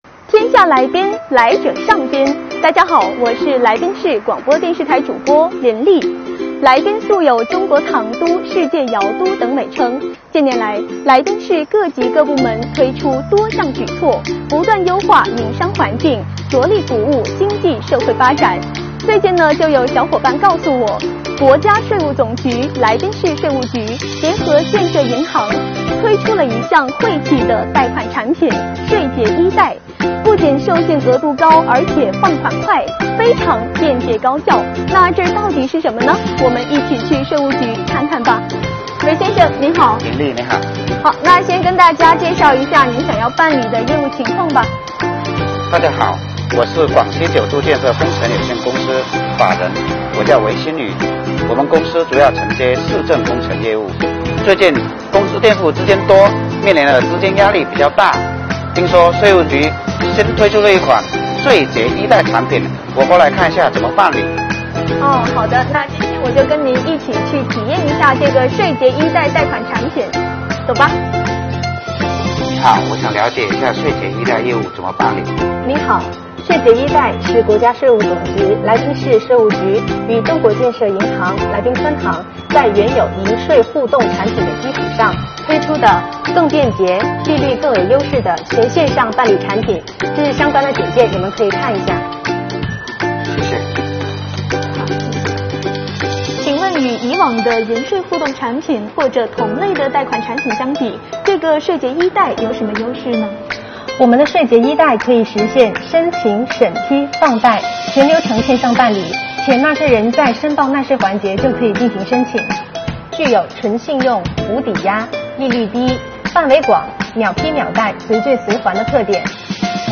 Vlog